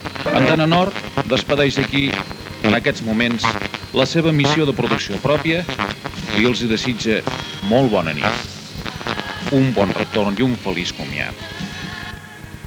Comiat de la programació.
FM